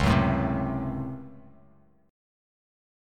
C#M7sus4#5 chord